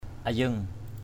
/a-zəŋ/ (cv.) yeng y$ 1.
ayeng.mp3